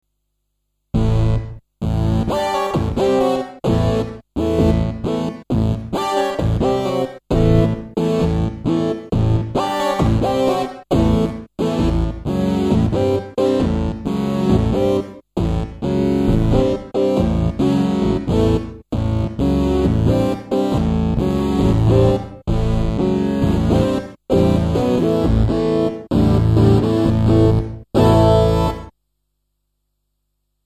The Yamaha DX7 digital programmable algorithm synthesizer.
Abaca... eehh A take on an early eighties type sound.